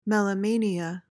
PRONUNCIATION:
(mel-uh-MAY-nee-uh)